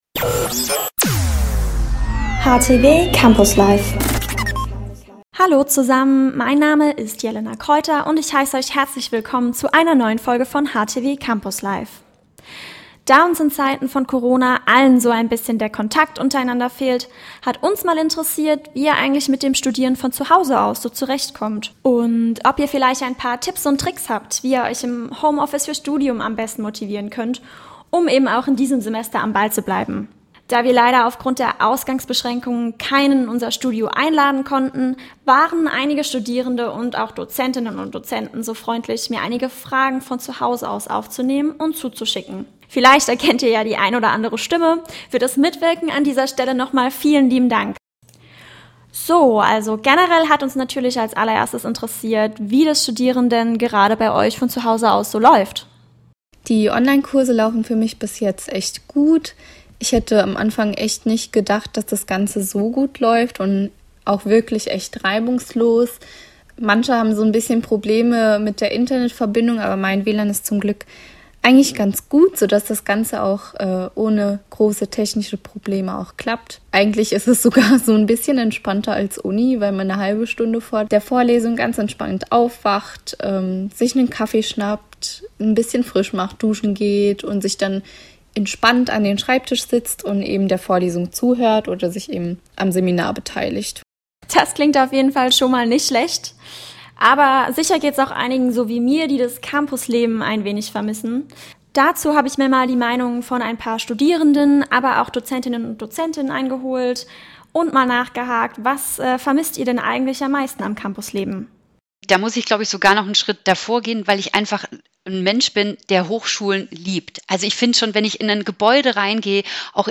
befragt Studierende und DozentInnen